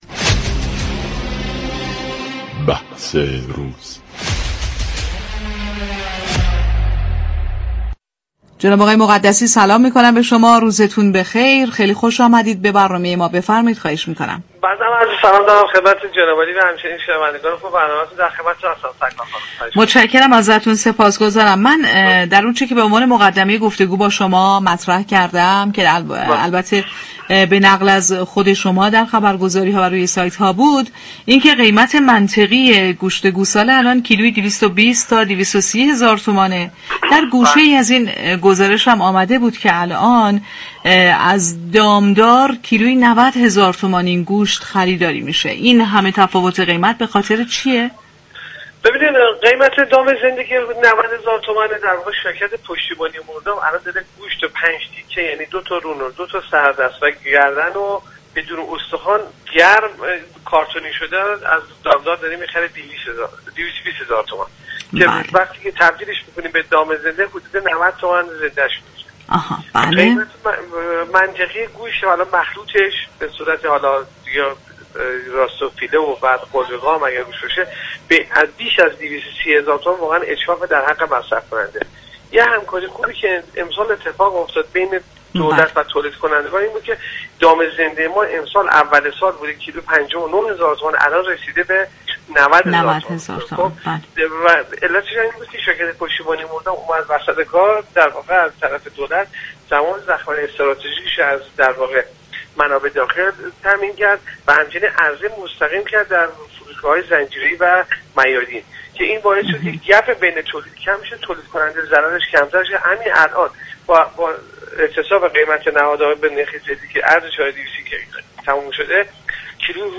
در گفت‌وگو با بازار تهران رادیو تهران